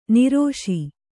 ♪ nirōṣi